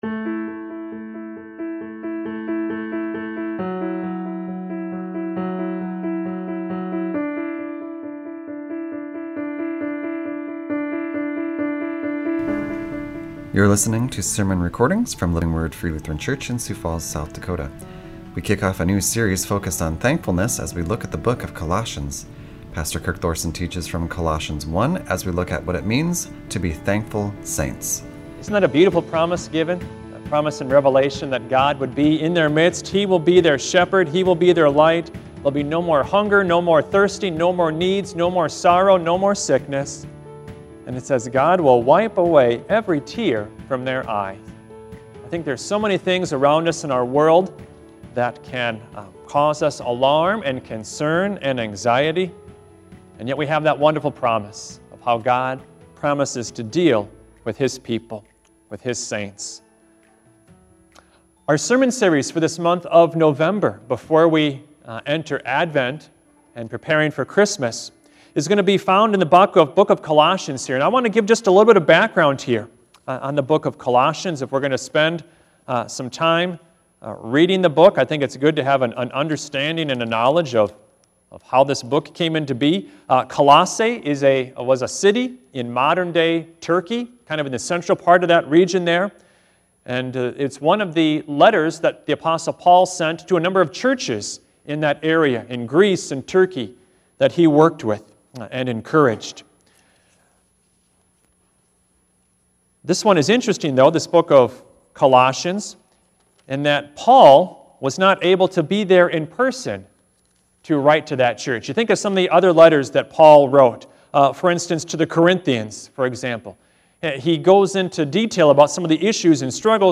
A Sermon from the Series "And Be Thankful."